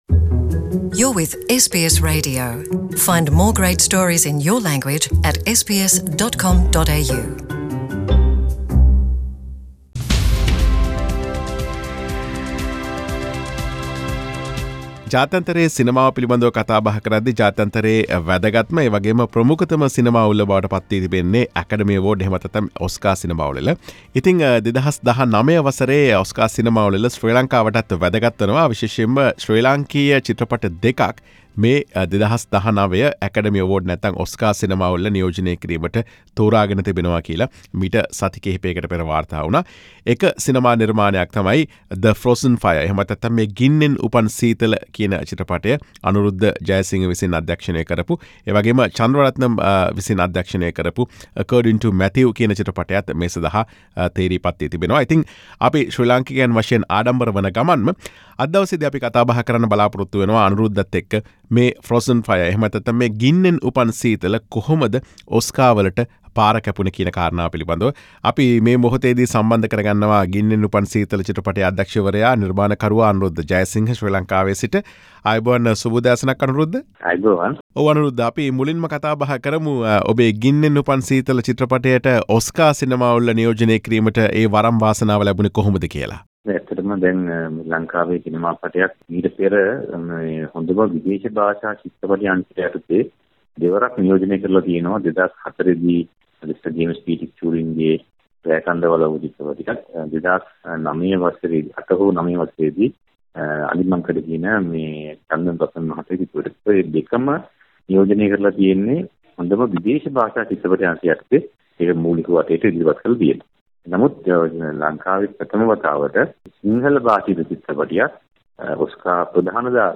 සිදුකළ සාකච්ඡාව